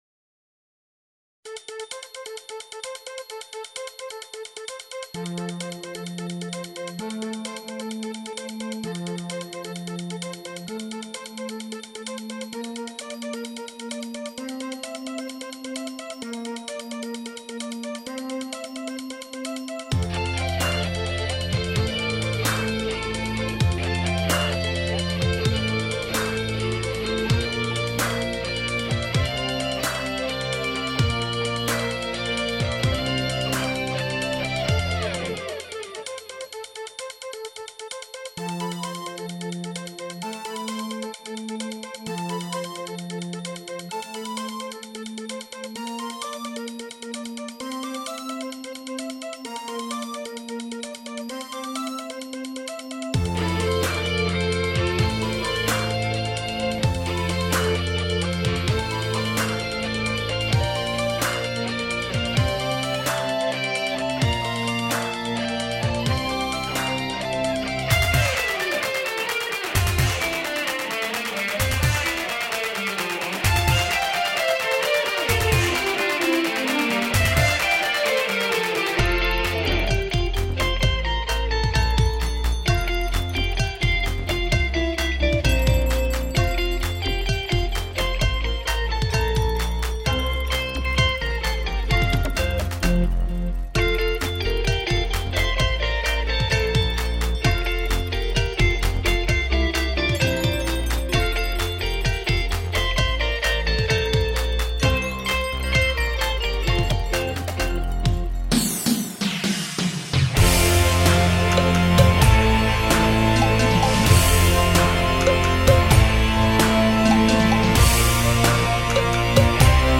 Whack-A-Long-Guitar-Mix.mp3